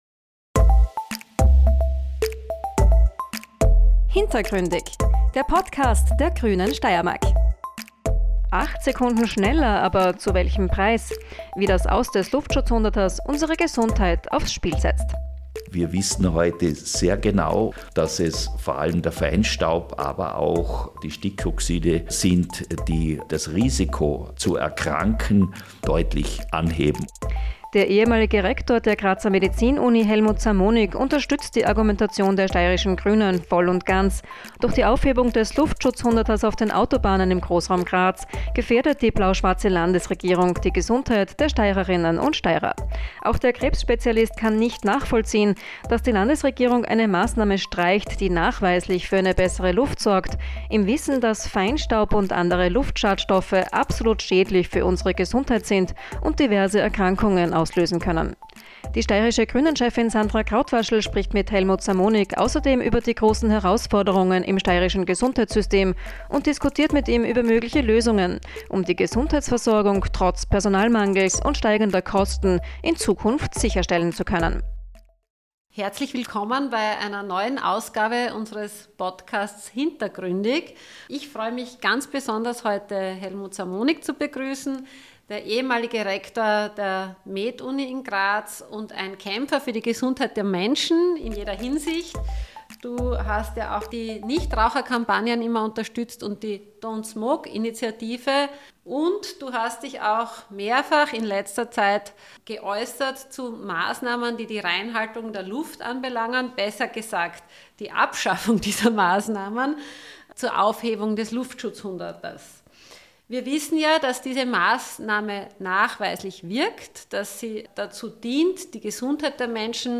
Landessprecherin Sandra Krautwaschl und unsere Landtagsabgeordneten laden ein zum Talk mit Tiefgang.